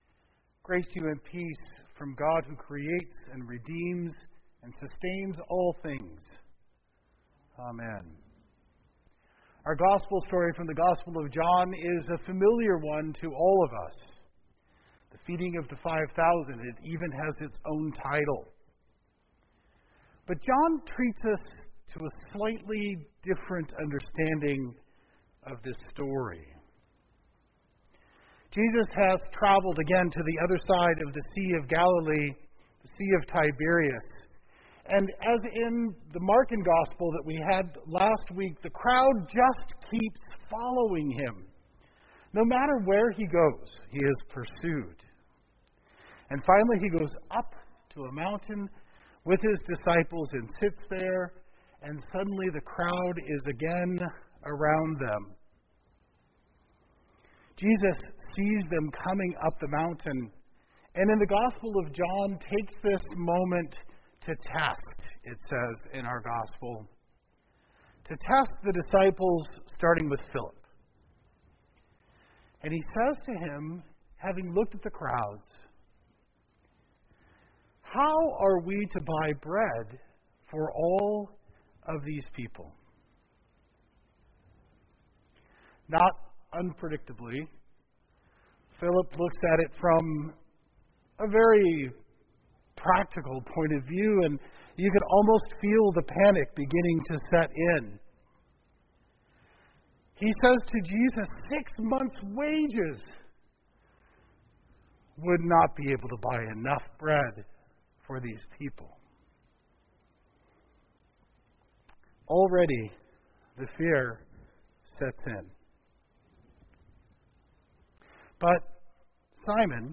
LCH Sermons—Time after Pentecost (May, June, July) 2021 (Year B) – Lutheran Church of Honolulu